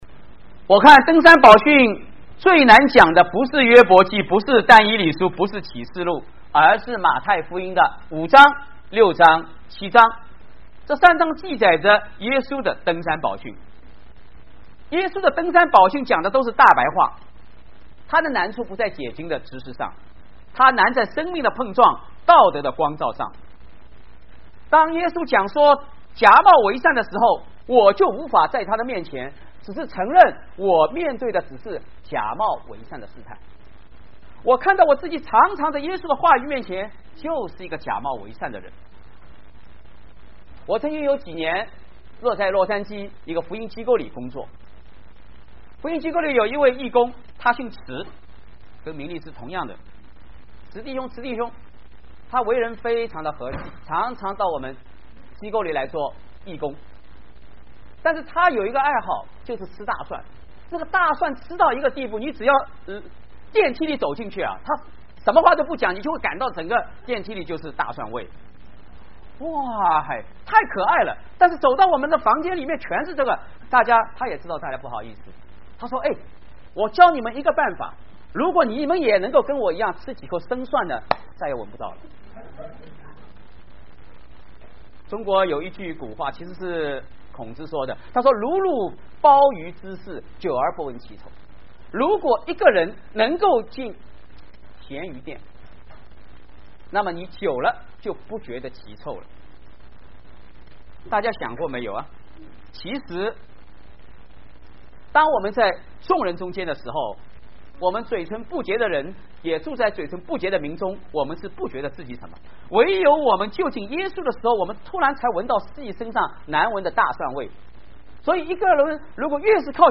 “ 禱告你們在暗中的父 ” 講員：